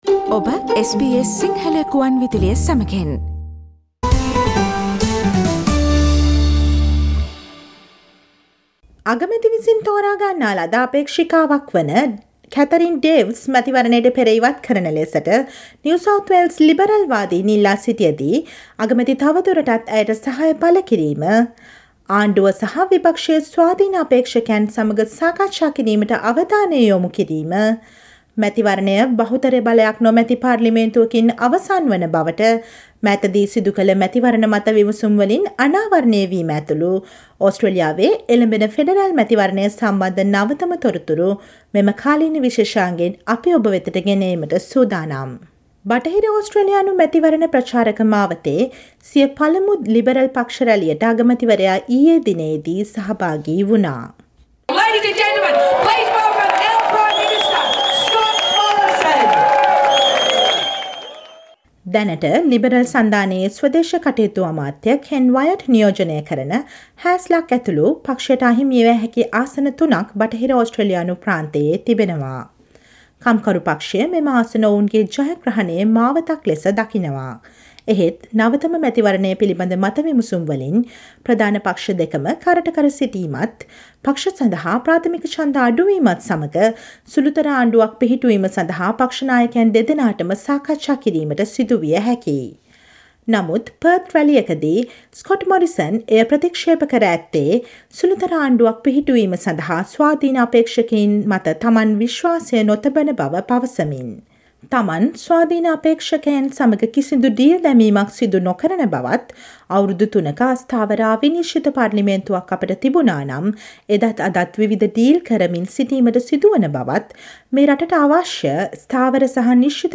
ඔස්ට්‍රේලියාවේ එළඹෙන ෆෙඩරල් මැතිවරණය සම්බන්ධ නවතම තොරතුරු ගැන දැන ගන්න අප්‍රේල් 21 වෙනි බ්‍රහස්පතින්දා ප්‍රචාරය වූ SBS සිංහල ගුවන්විදුලි සේවයේ කාලීන තොරතුරු විශේෂාංගයට සවන් දෙන්න.